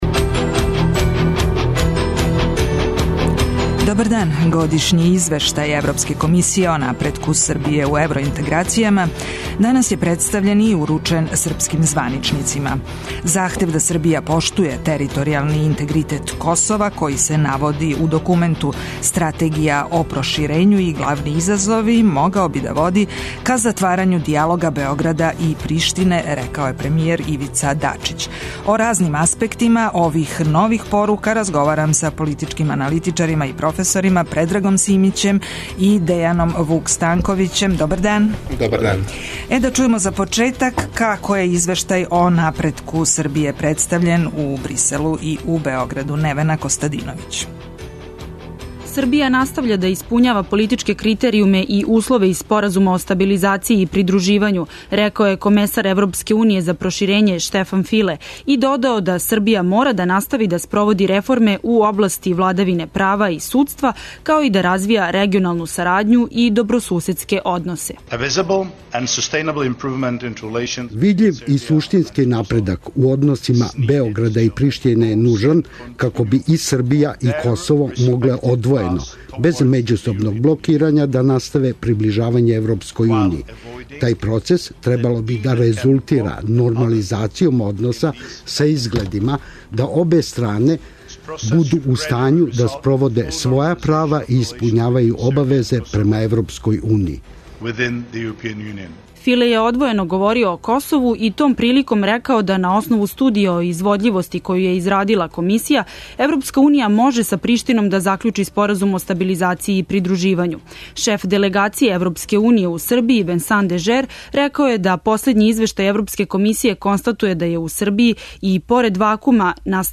доноси интервју са нашим најбољим аналитичарима и коментаторима, политичарима и експертима